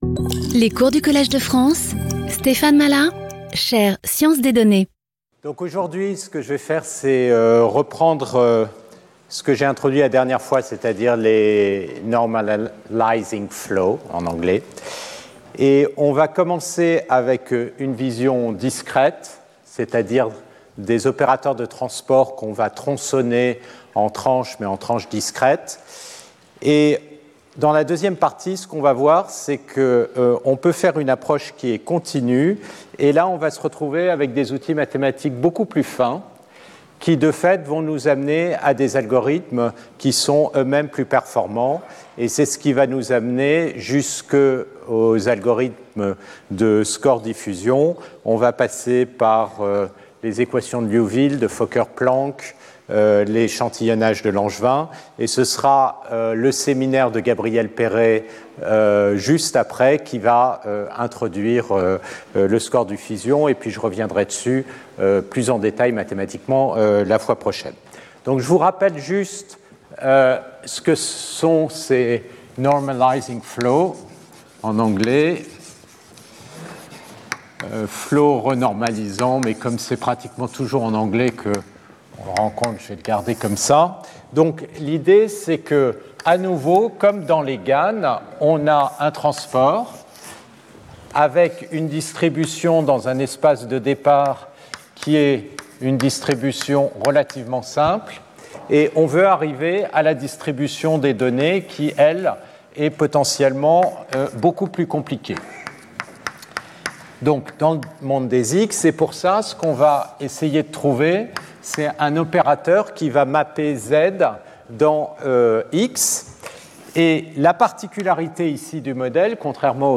Speaker(s) Stéphane Mallat Professor at the Collège de France
Lecture